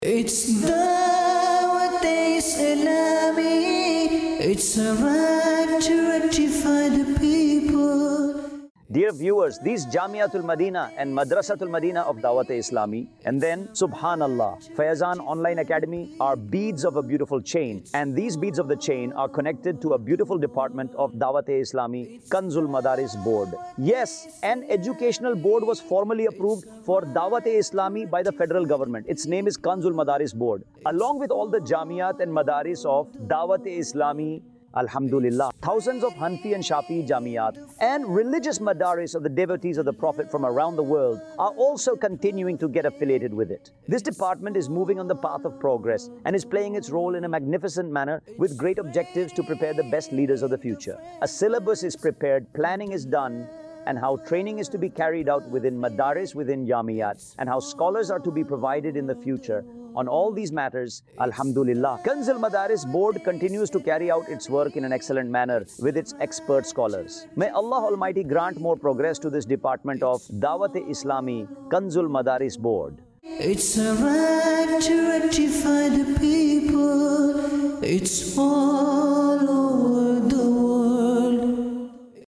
khutba
Kanz-ul-Madaris Board | Department of Dawateislami | Documentary 2026 | AI Generated Audio